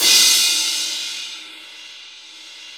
Index of /90_sSampleCDs/Sound & Vision - Gigapack I CD 1 (Roland)/CYM_K-CRASH st/CYM_K-Crash st 2
CYM CRA3406L.wav